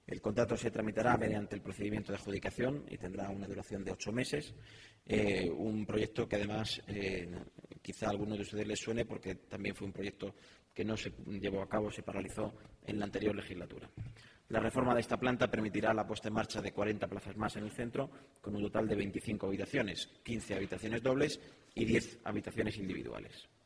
Vicepresidencia Primera Miércoles, 3 Abril 2019 - 11:00am El portavoz ha señalado que el contrato se tramitará mediante el procedimiento de adjudicación y tendrá una duración de ocho meses, después de que fuese paralizada en la anterior legislatura. La reforma de esta planta permitirá la puesta en marcha de 40 plazas más en el Centro, con un total de 25 habitaciones (15 dobles y 10 individuales). corte_hernando_pocitas_prior.mp3 Descargar: Descargar